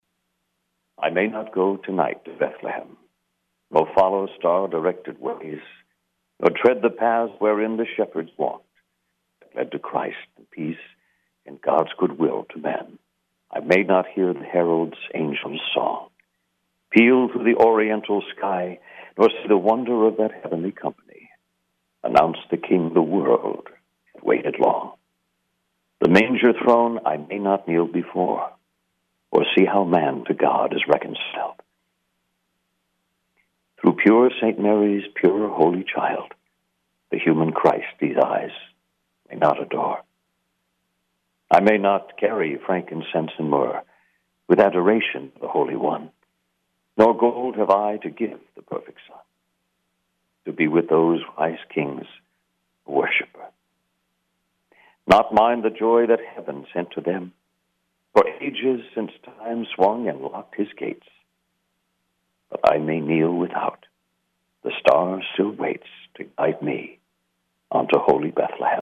John O’Hurley joined us on our Downtown Christmas Special and performed a reading of “Christmastide” by Emily Pauline Johnson.